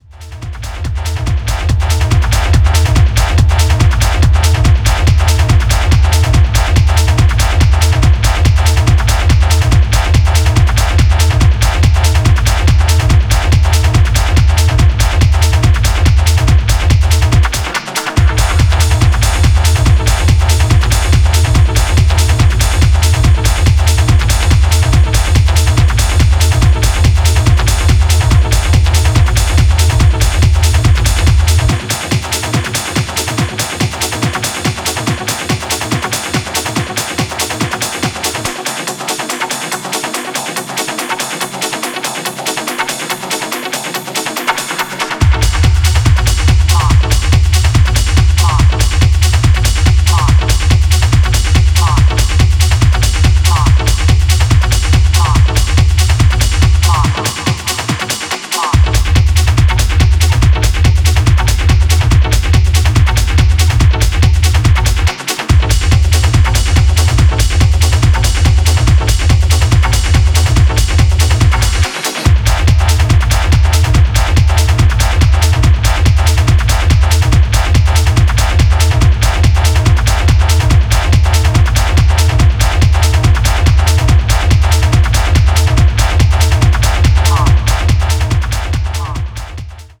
Hardgroove